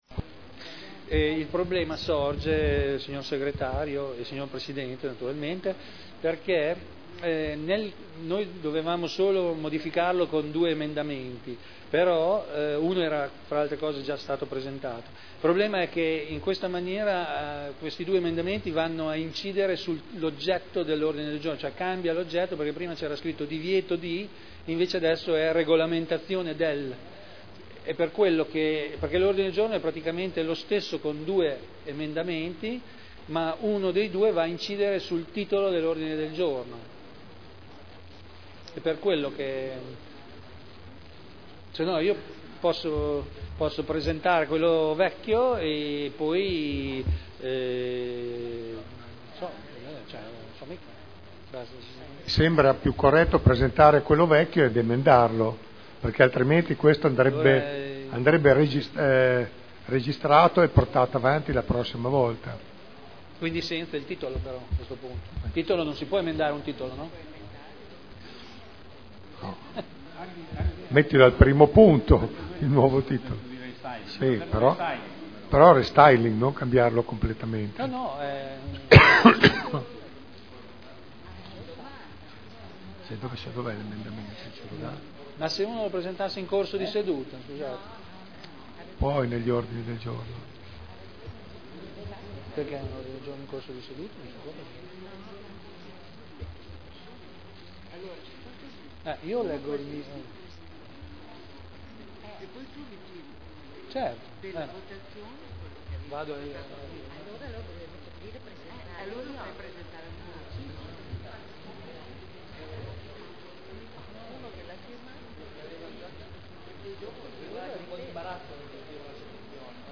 Vittorio Ballestrazzi — Sito Audio Consiglio Comunale